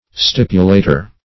stipulator - definition of stipulator - synonyms, pronunciation, spelling from Free Dictionary Search Result for " stipulator" : The Collaborative International Dictionary of English v.0.48: Stipulator \Stip"u*la`tor\, n. [L.]